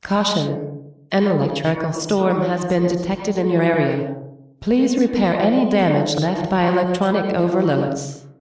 electronicoverload.ogg